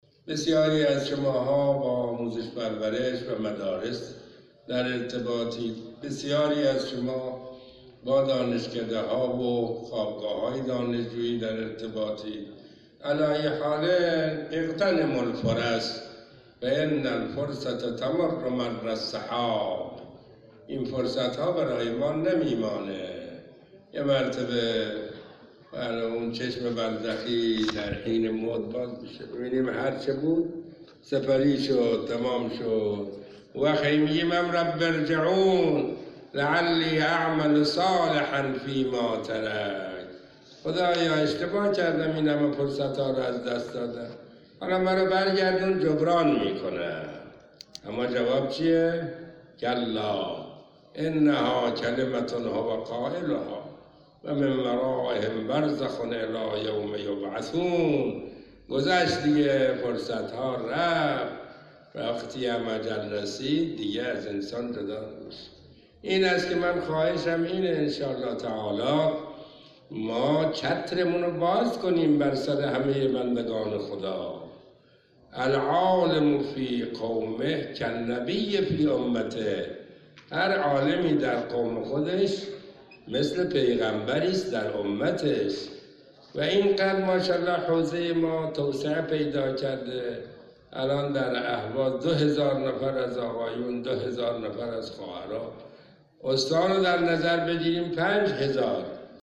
به گزارش خبرنگار خبرگزاری رسا در اهواز، آیت الله سید محمدعلی موسوی جزایری، عصر امروز، در همایش استانی بیانیه گام دوم انقلاب اسلامی در تالار شهید جمالپور، با بیان اینکه روحانیت خواسته‌های رهبری را به مردم و خواسته‌های مردم را به مسئولان منتقل کند، اظهار داشت: از آنجا که روحانیت با مدارس، دانشگاه ها، مساجد و خوابگاه‌های دانشجویی در ارتباط است باید بهترین استفاده را از چنین فرصتی ببرد.